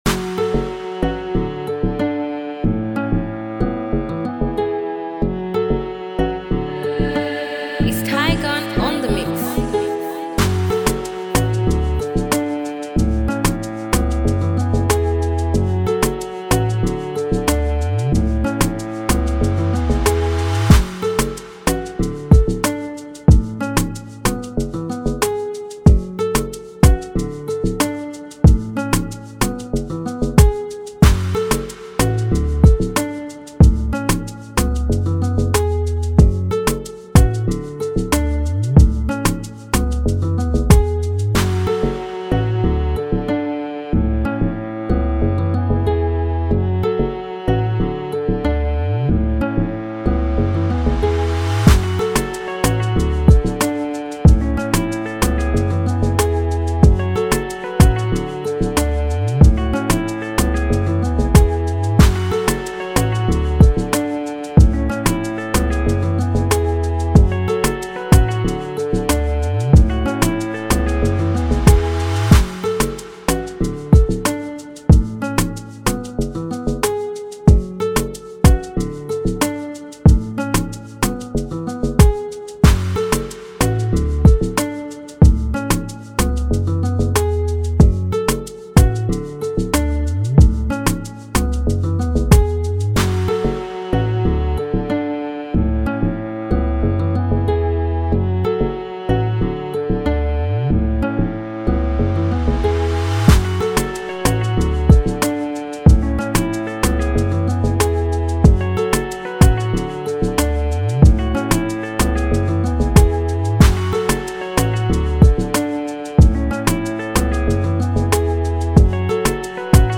FreeBeats